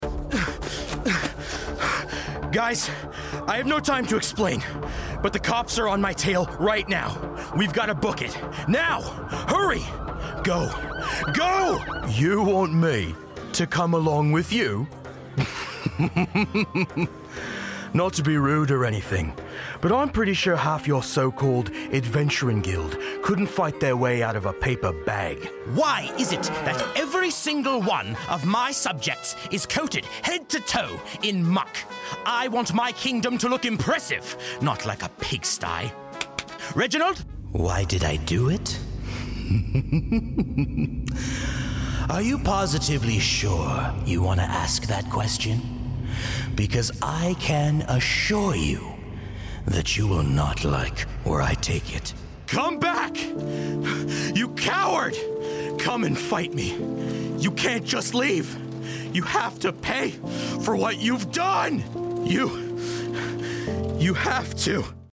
Videogames
Em relação ao meu estúdio caseiro, uso um microfone U87 passando por uma interface Universal Audio Volt. Minha cabine é totalmente tratada com painéis acústicos feitos de Rockwool Safe n' Sound.
Inglês (canadense)
Barítono